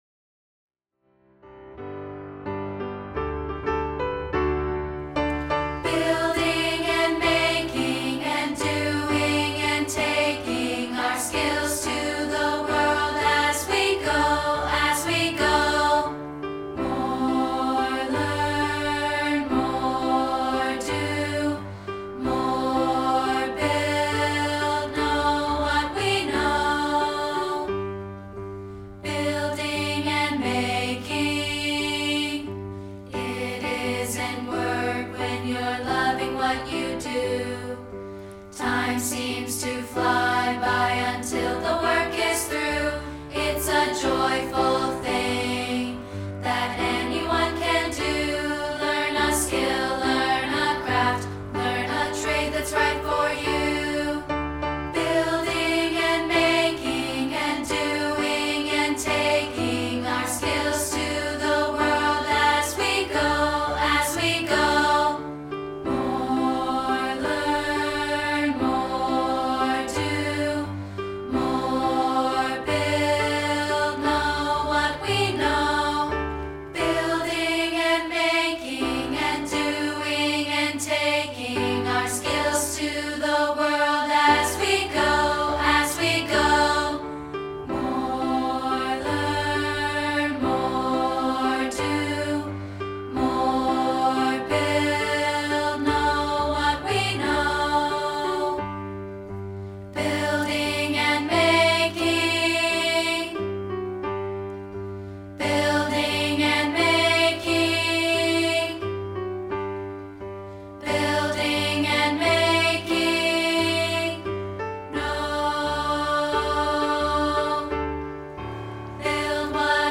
We're offering a rehearsal track of part 2, isolated